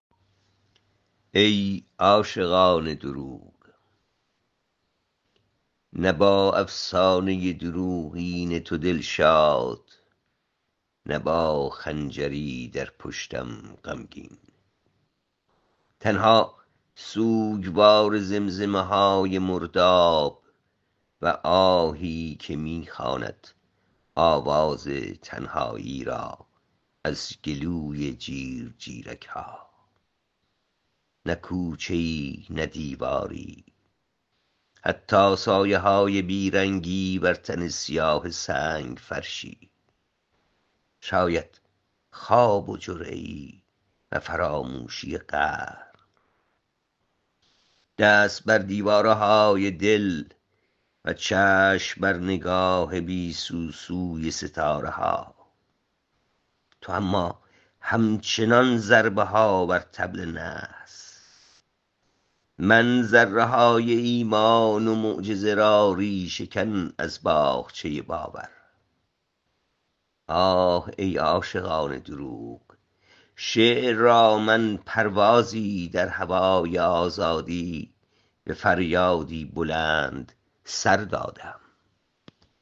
این شعر را با صدای شاعر بشنوید